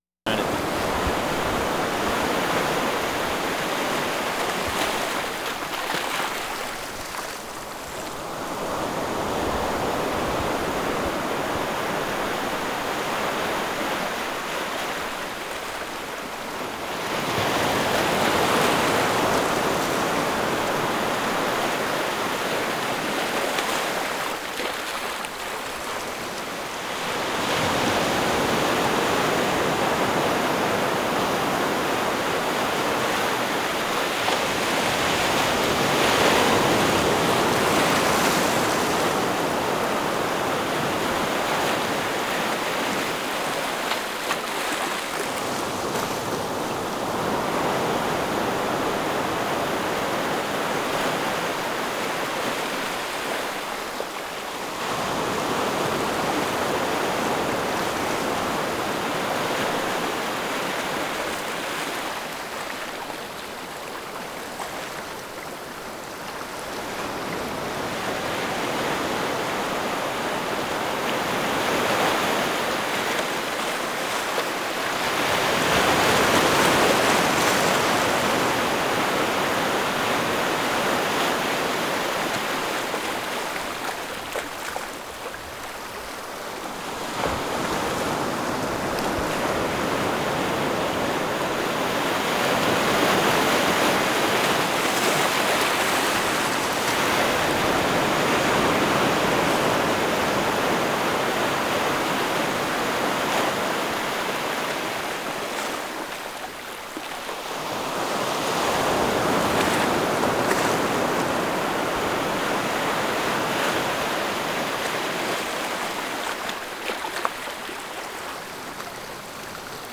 WORLD SOUNDSCAPE PROJECT TAPE LIBRARY
CHINA BEACH, FRENCH BEACH, ETC. NOV. 10, 1991
China Beach, ocean waves 3:45